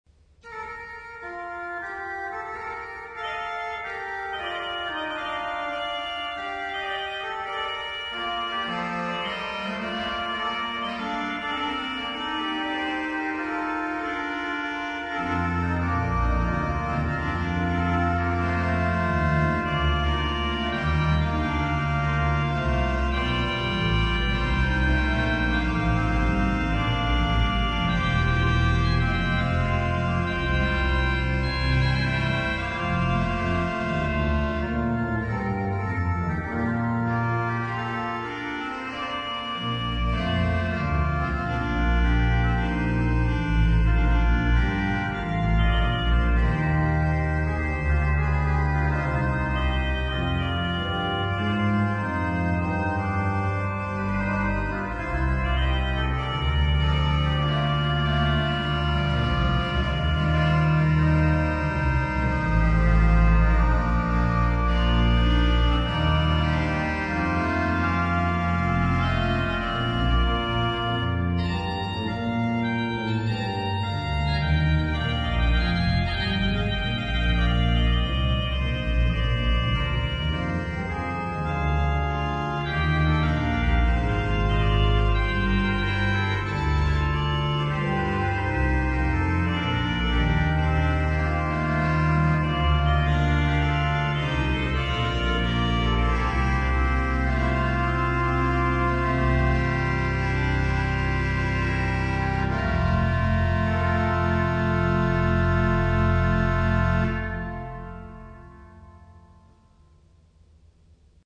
L'Orgue de St Rémy de Provence